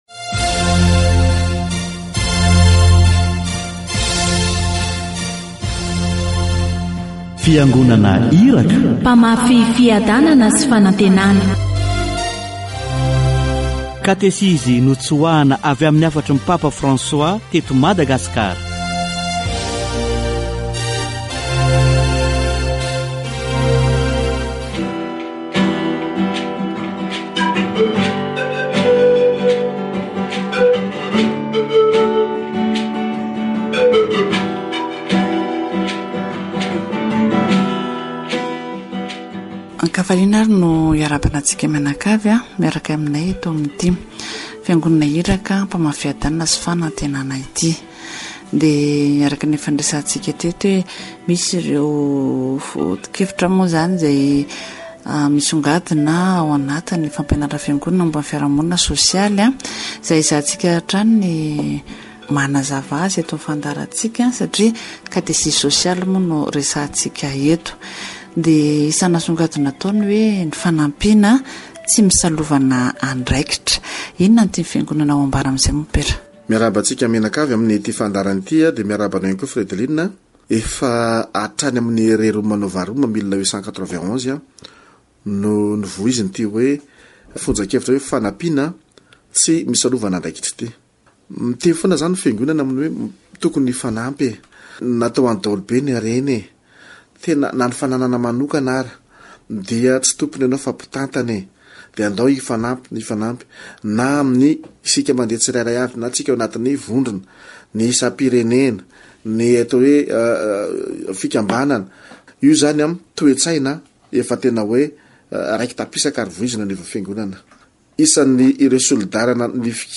All groups at the higher level must be willing to give help to support or develop the lower groups. Catechesis on social ministry